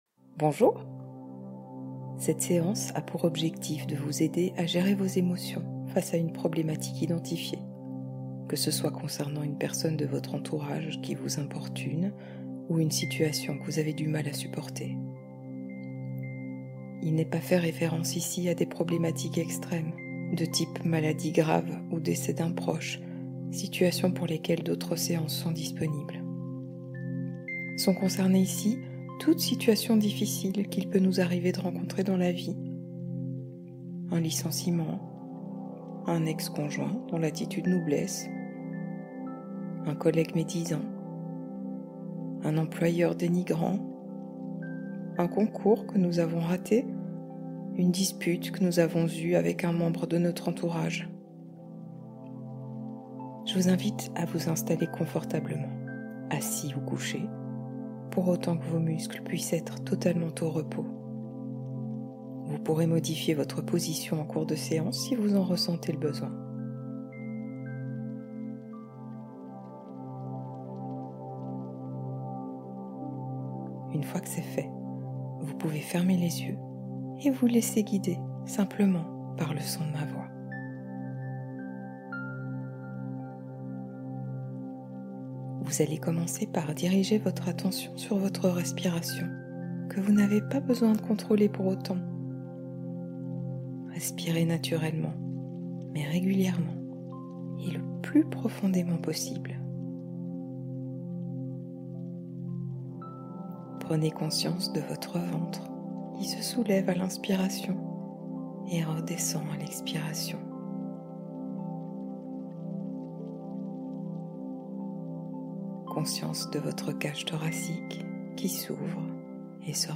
Dépression / Burn-out : hypnose pour comprendre et se relever